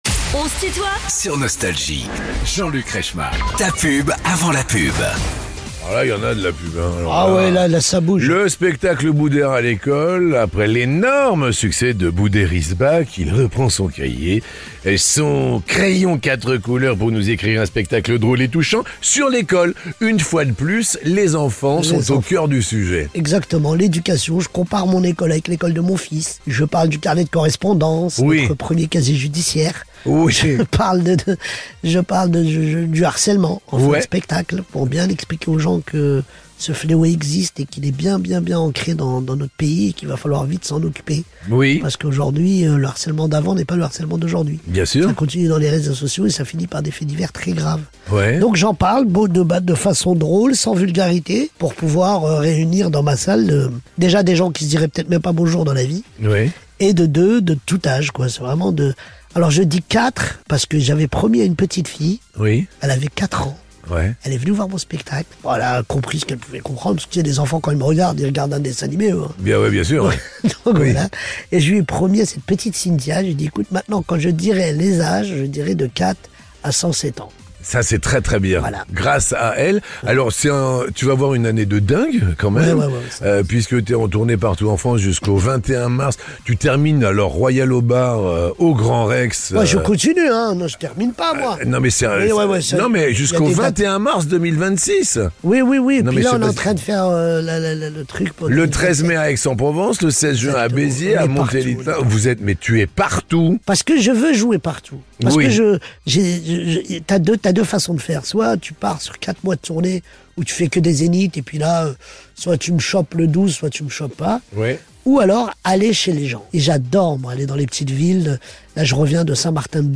À l’affiche de la série Nounou diffusée tous les lundis soir sur TF1, mais également en tournée dans toute la France avec son spectacle intitulé "Ah... l’école !", l'humoriste et comédien Booder est l'invité de Jean-Luc Reichmann dans son émission "On se tutoie ?...", vendredi 2 mai de 19h à 20h.